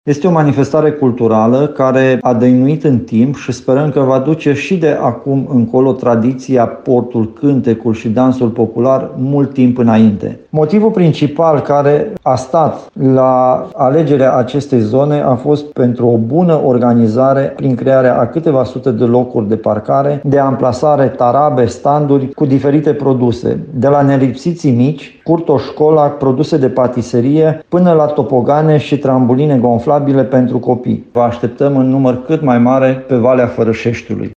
Schimbarea locului de desfăşurare, a permis o mai ușoară organizare, spațiul fiind mai mare decât la Valea lui Liman, precizează primarul comunei Tomești, Costel Medelean.